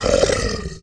1 channel
Blood_voice_volley.mp3